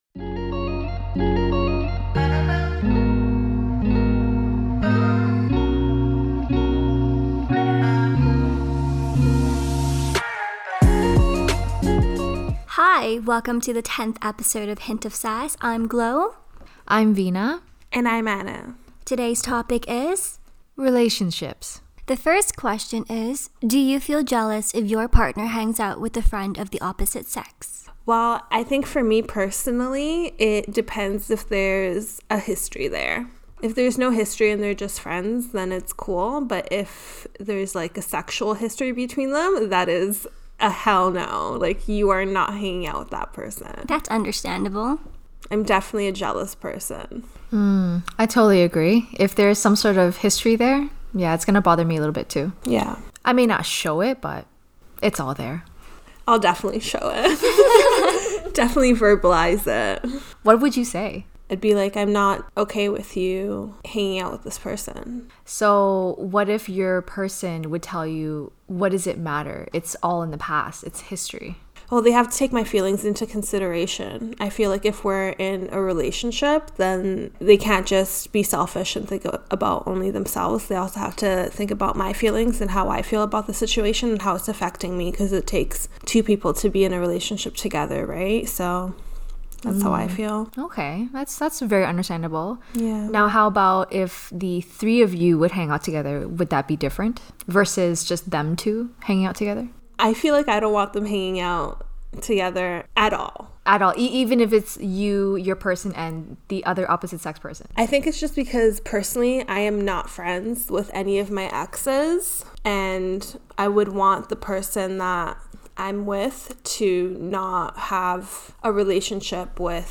Play Rate Listened List Bookmark Get this podcast via API From The Podcast A trio of ladies discussing everyday topics with a hint of sass. Expect authentic, unfiltered and unapologetic discussion on everyday struggles.